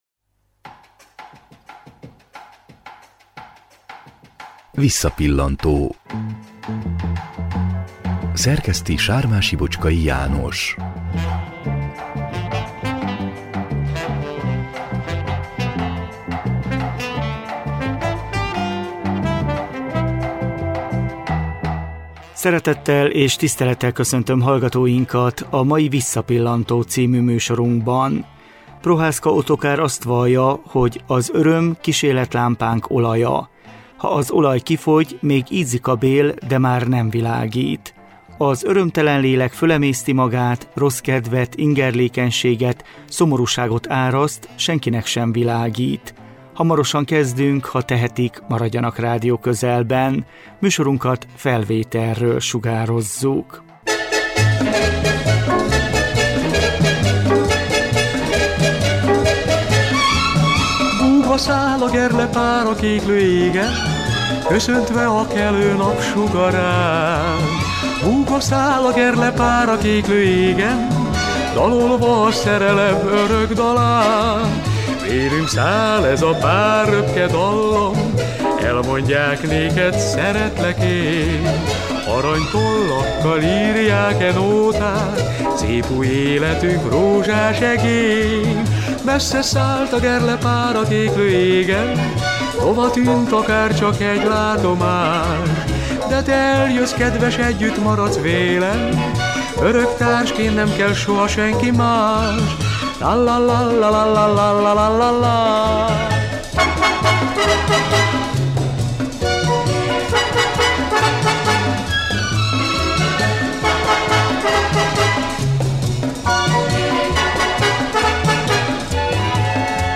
Műsorunk végén a barcaszentpéteri Nicodemus Öregotthon lakóival ismerkedünk meg egy 2003-ban készült hangfelvételről.